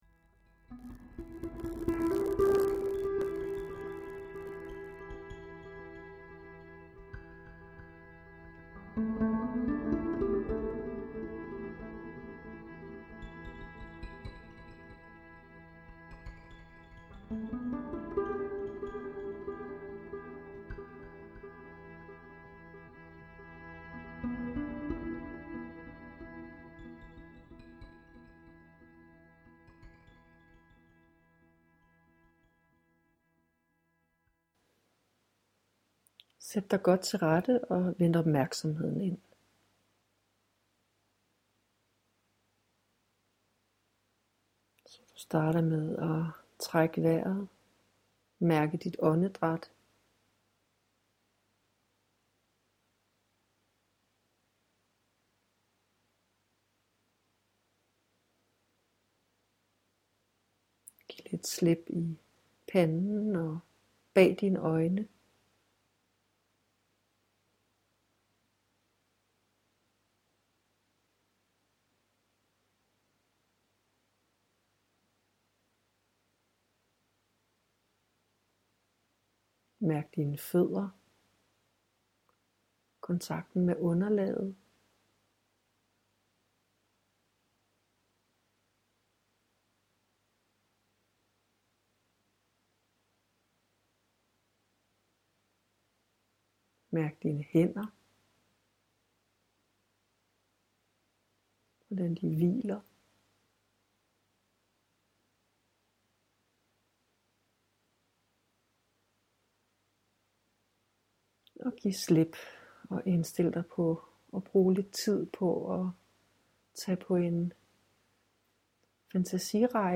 Optagelsen er ikke i topkvalitet, men nu ligger den til brug…..